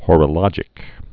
(hôrə-lŏjĭk, hŏr-) also hor·o·log·i·cal (-ĭ-kəl)